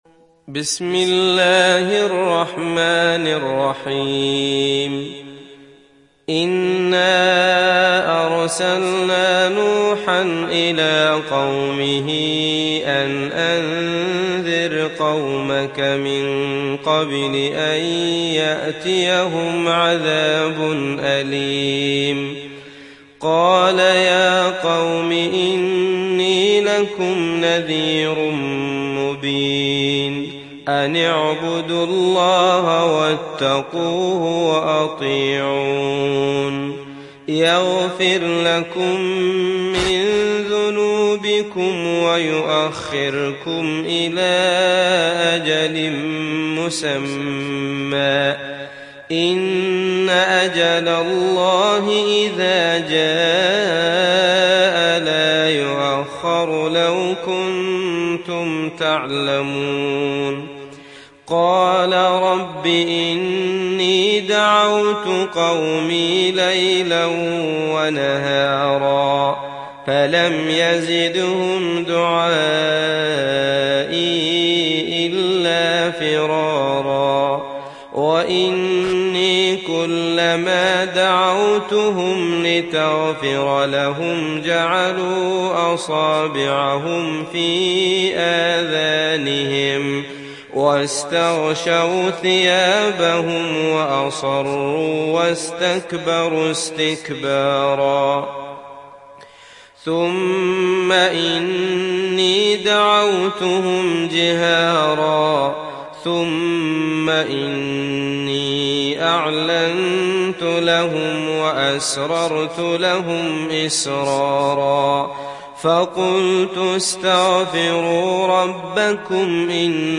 Sourate Nuh Télécharger mp3 Abdullah Al Matrood Riwayat Hafs an Assim, Téléchargez le Coran et écoutez les liens directs complets mp3